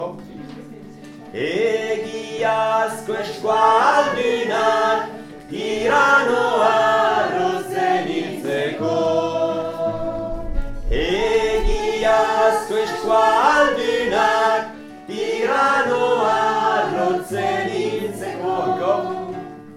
femmes_part3.mp3